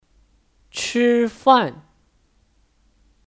吃饭 (Chīfàn 吃饭)